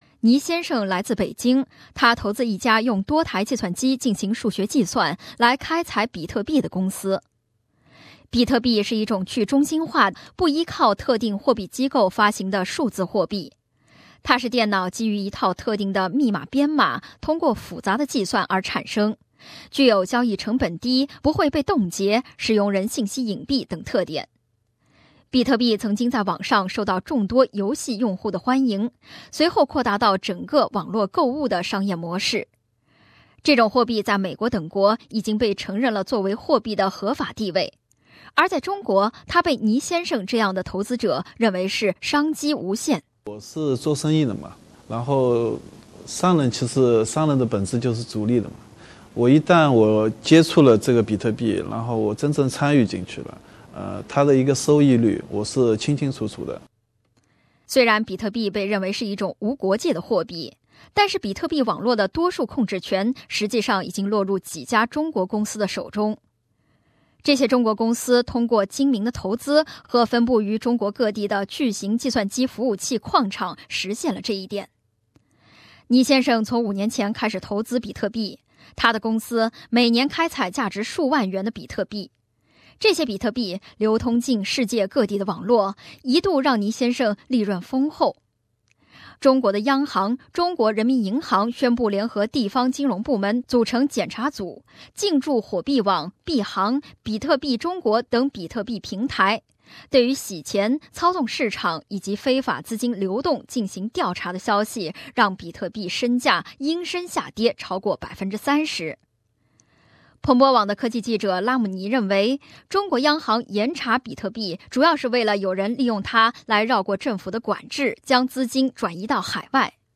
SBS Mandarin morning news